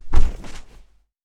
Foley Sports / Skateboard / Bail Normal.wav
Bail Normal.wav